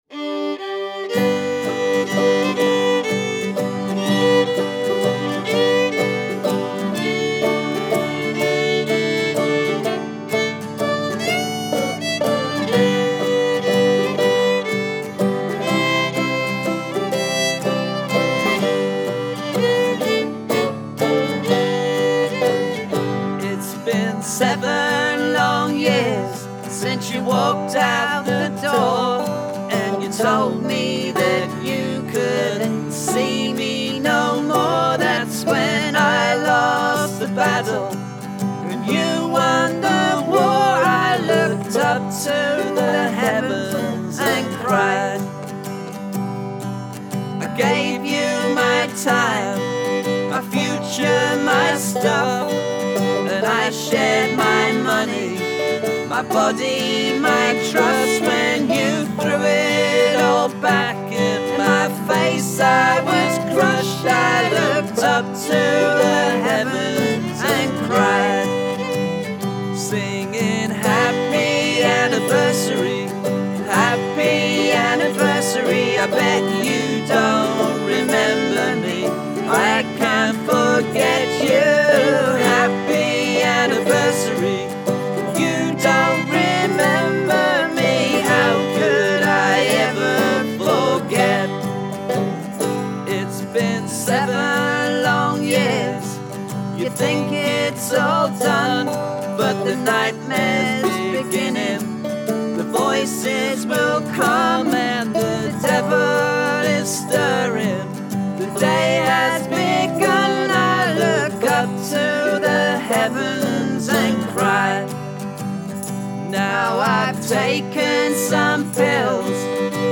How long have we got left anyway? these, and more, it seems, are emotions still to be found at the end of the (Victoria) line, wrapped in acoustic guitar- driven, fiddle-splashed, harmony-filled, lovely London folk music!
A tuneful waltz, on the surface, perhaps, a celebratory song, but as soon as the words start, it becomes apparant that it is not a happy anniversary. The character in the song breaks down every year on the day that her lover left her, and the pain is carried in the lyrics, with the bitterness clearly detectable in the singing of the Happy Anniversarys of the chorus.
The song is sung in male/female harmony throughout, in a country style.
It was very enjoyable to add banjo, harmonica, 2nd guitar there and then, with limited time. Without too much time to get precious about the arangements, and as well as being a crystal clear recording, it has come out with a nice natural feel.